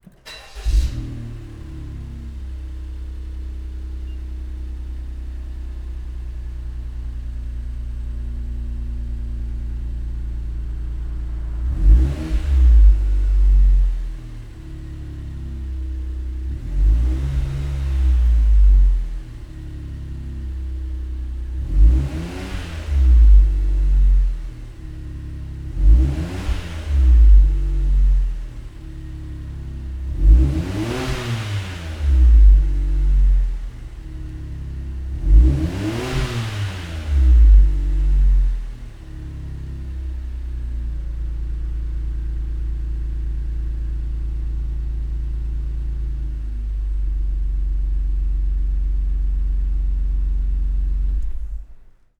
LA400 ﾏﾌﾗｰｻｳﾝﾄﾞ | 株式会社SC force